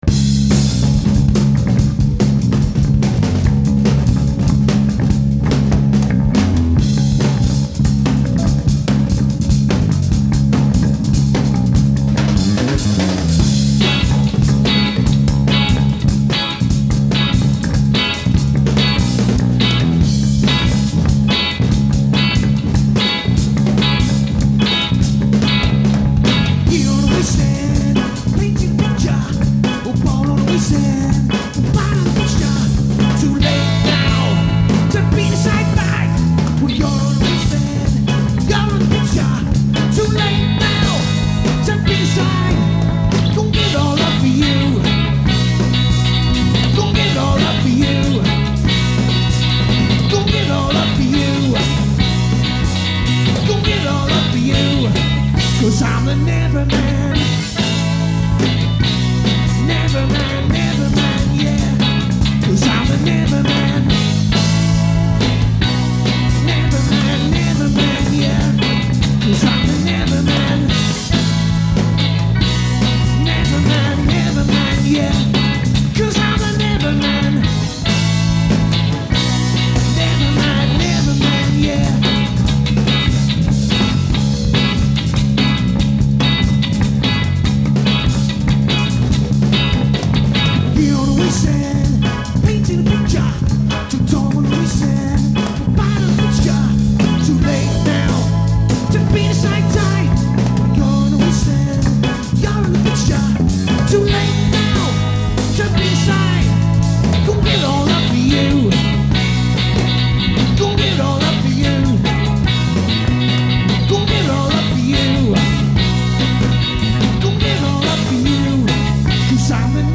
These were recorded on two track in the rehearsal room
There are no Backing vocals as we ran out of mics